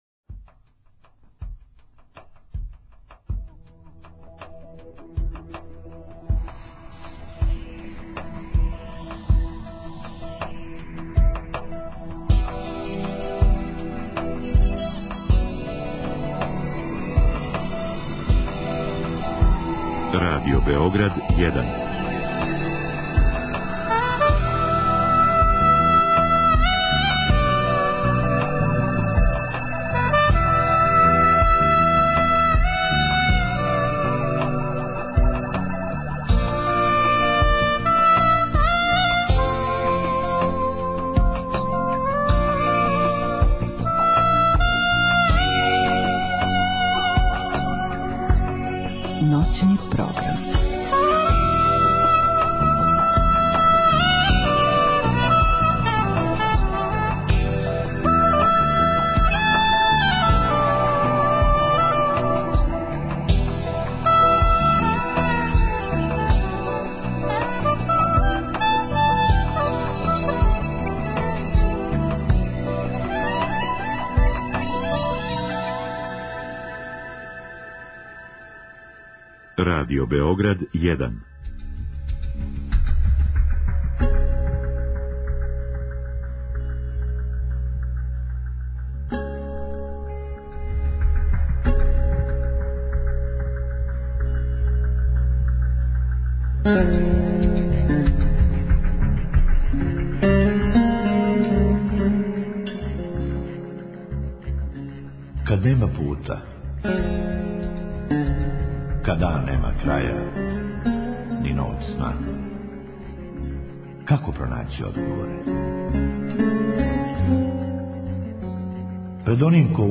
У другом сату емисије слушаоци обликују емисију својим исповестима, коментарима, предлозима.